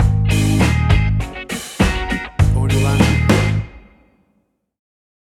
Classic reggae music with that skank bounce reggae feeling.
Tempo (BPM): 100